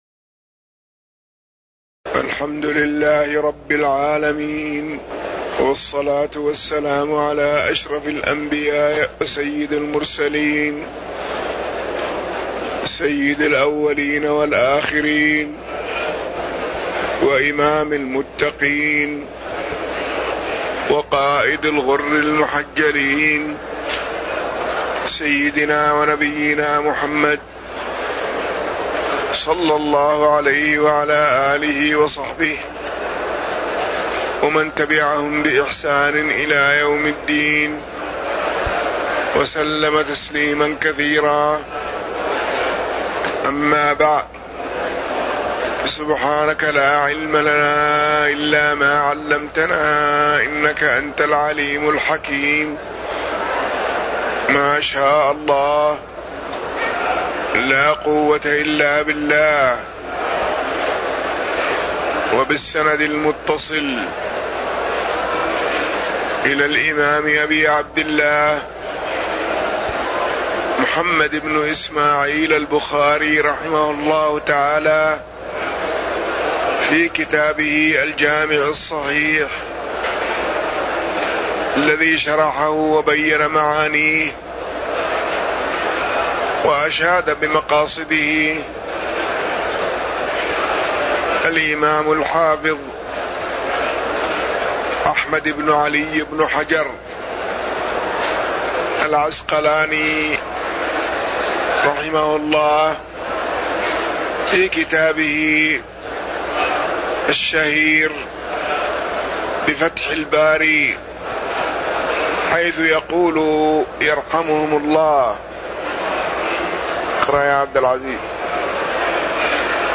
تاريخ النشر ١٥ شعبان ١٤٣٩ هـ المكان: المسجد النبوي الشيخ